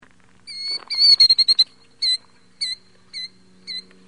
killdeer.mp3